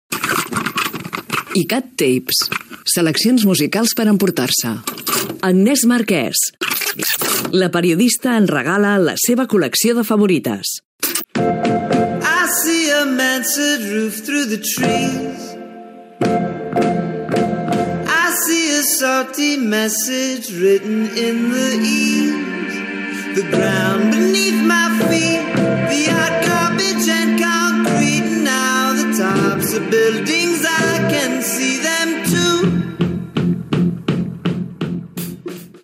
Careta del programa.
Musical